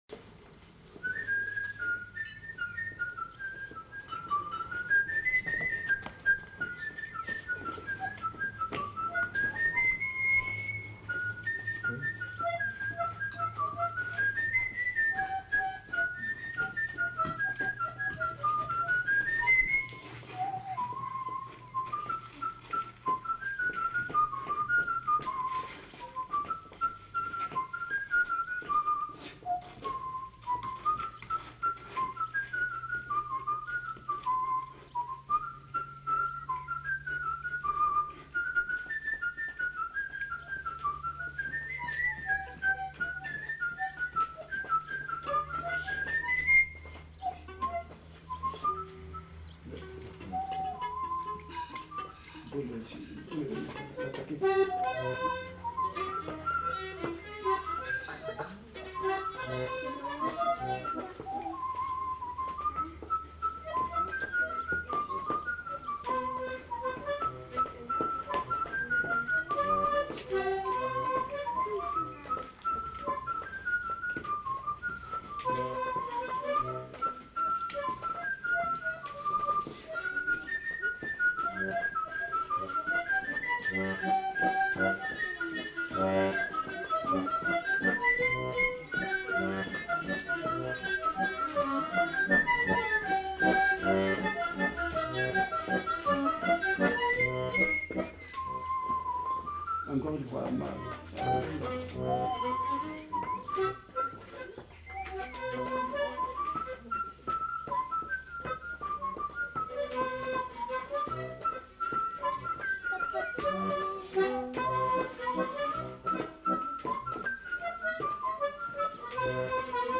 Mazurka (sifflé)
Aire culturelle : Agenais
Lieu : Foulayronnes
Genre : chant
Effectif : 1
Type de voix : voix d'homme
Production du son : sifflé
Notes consultables : Les enquêteurs apprennent le morceau en même temps.